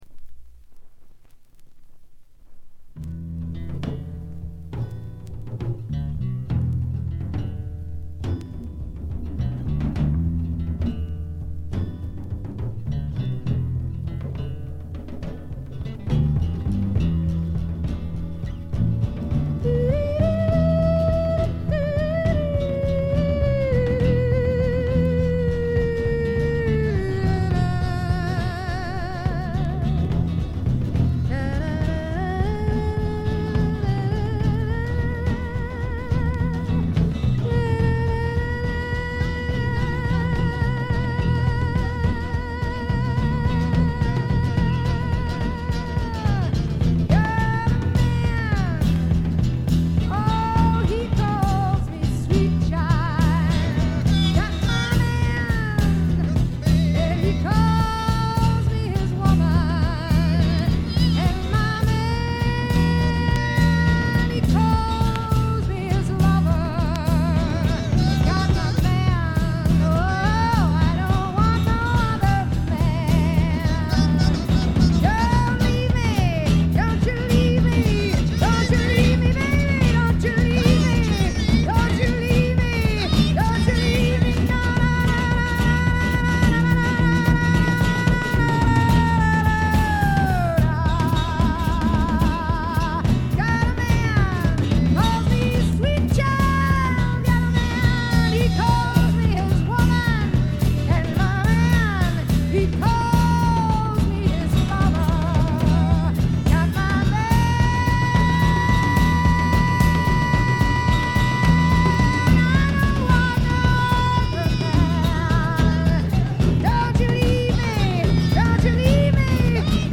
ところどころでチリプチ。大きなノイズはありません。
試聴曲は現品からの取り込み音源です。